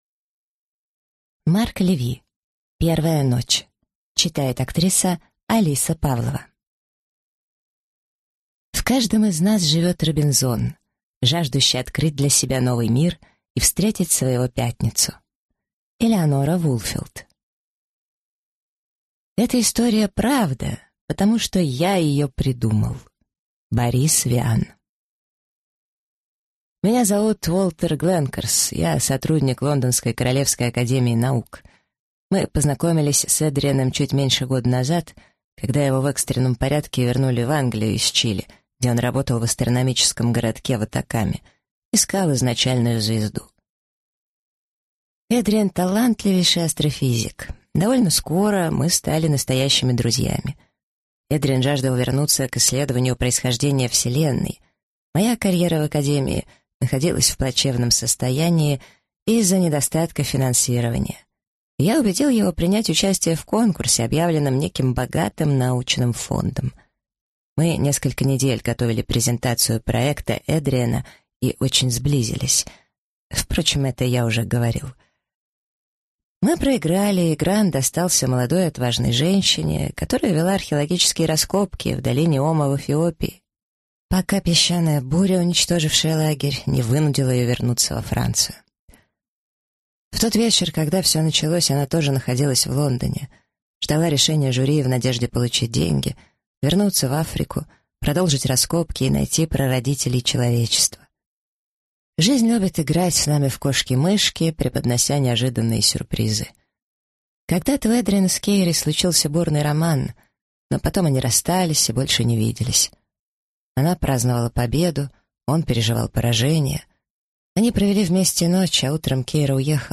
Аудиокнига Первая ночь | Библиотека аудиокниг